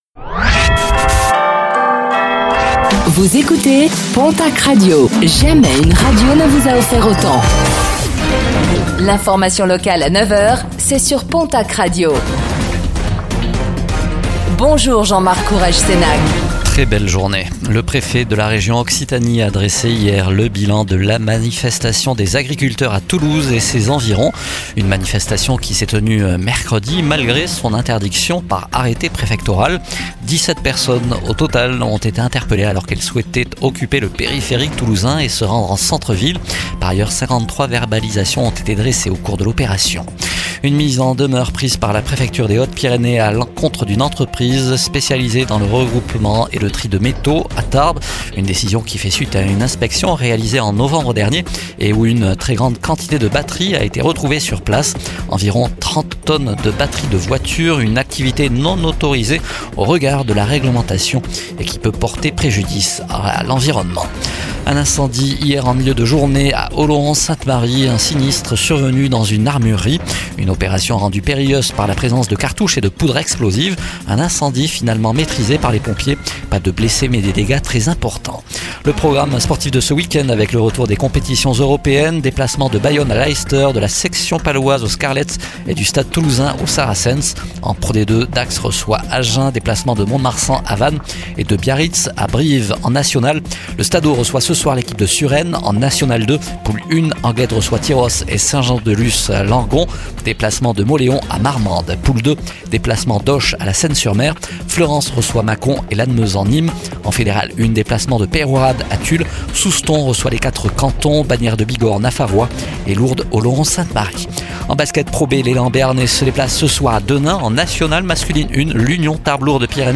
Flash Info Author: Pontacq Radio Language: fr Genres: News Contact email: Get it Feed URL: Get it iTunes ID: Get it Get all podcast data Listen Now...